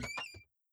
DrawerDoorOpening.wav